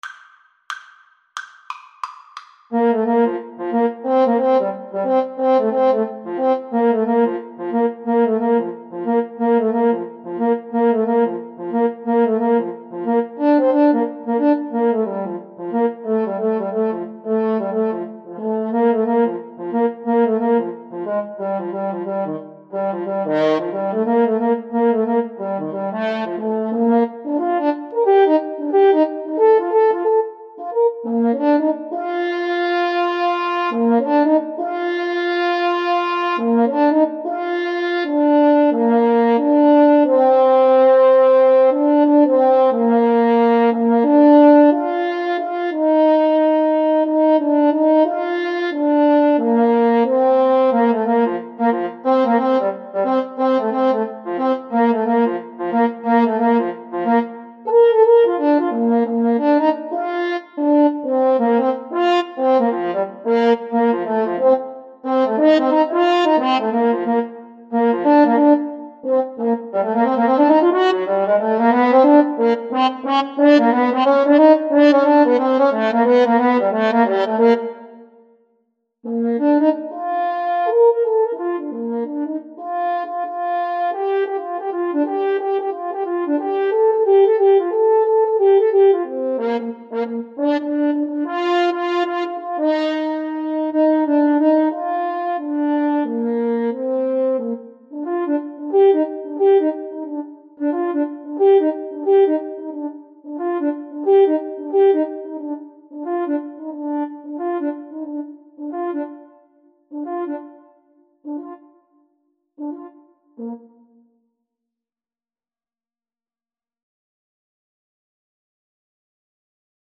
French Horn 1French Horn 2
2/2 (View more 2/2 Music)
Fast and with a swing =c.90
Classical (View more Classical French Horn Duet Music)